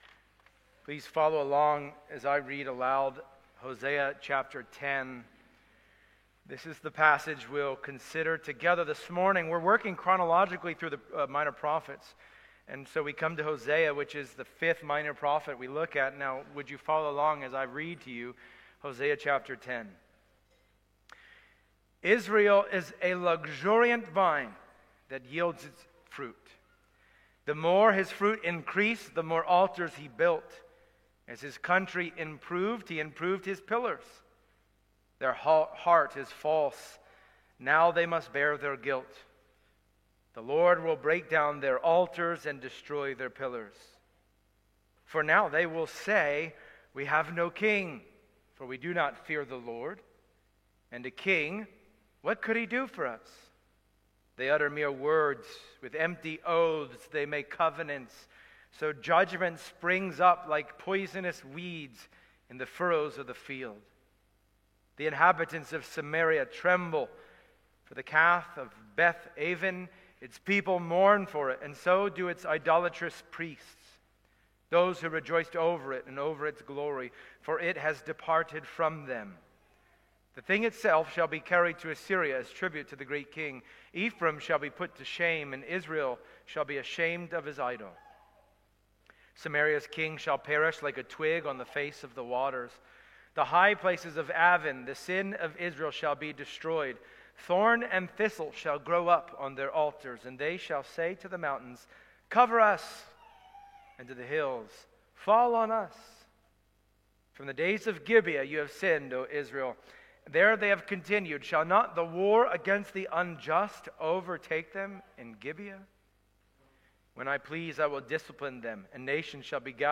Passage: Hosea 10 Service Type: Sunday Morning